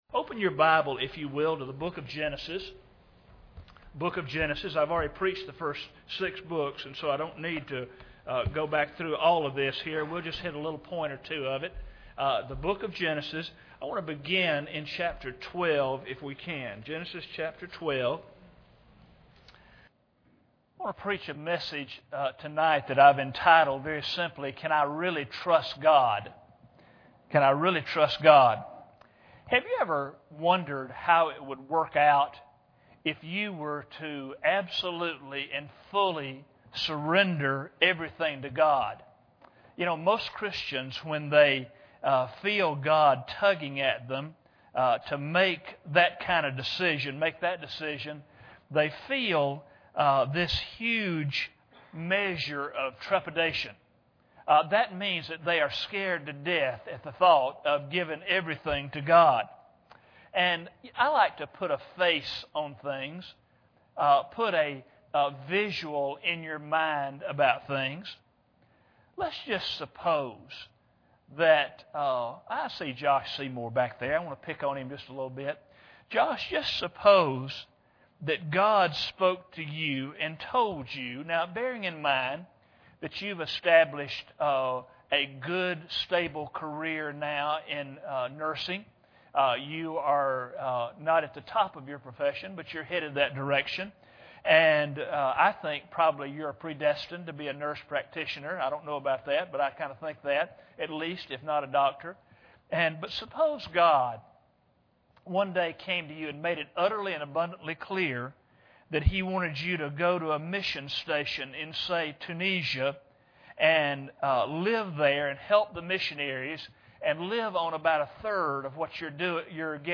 Genesis 18:25 Service Type: Sunday Evening Bible Text